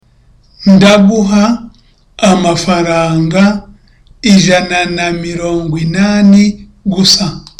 (Smiling)
(softly)